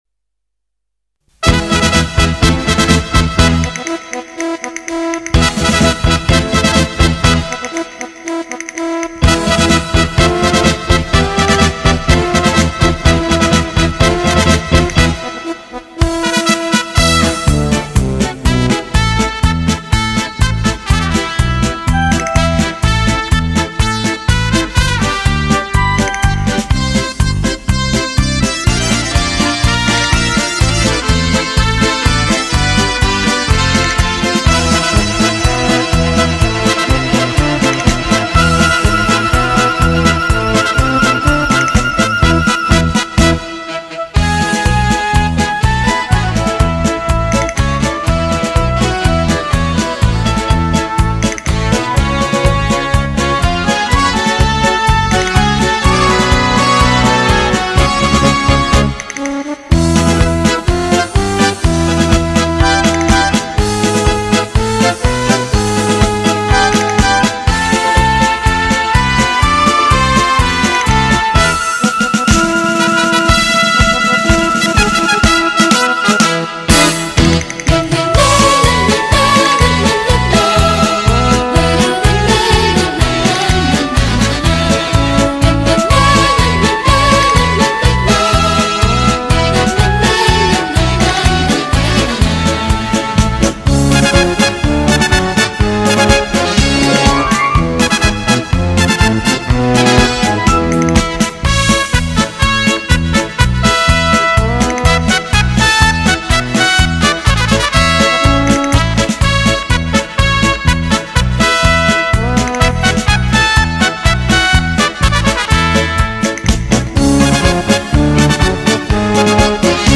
【外国音乐交谊舞曲】